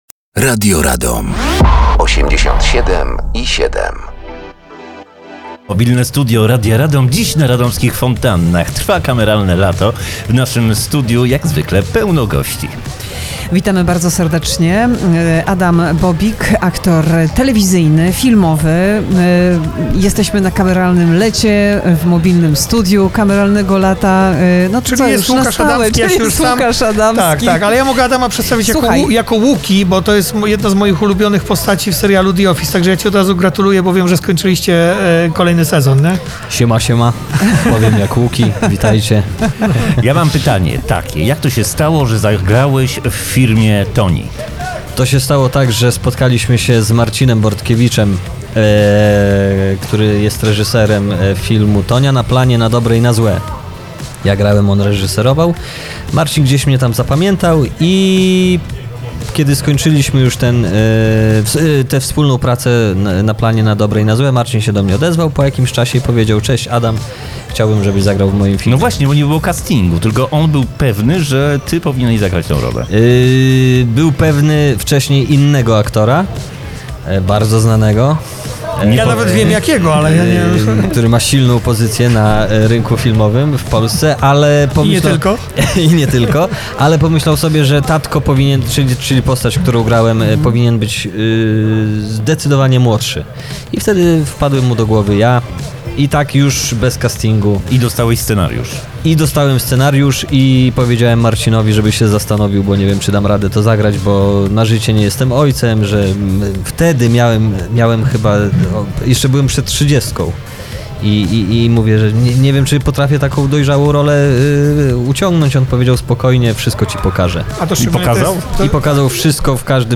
W Radomiu trwają 18. Międzynarodowe Spotkania Filmowe Kameralne Lato. Radio Radom zostało Głównym Partnerem Medialnym tego wydarzenia i Mobilne Studio każdego dnia towarzyszy festiwalowi.